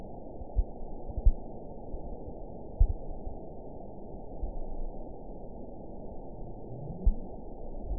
event 921015 date 04/23/24 time 23:48:52 GMT (1 year ago) score 5.53 location TSS-AB04 detected by nrw target species NRW annotations +NRW Spectrogram: Frequency (kHz) vs. Time (s) audio not available .wav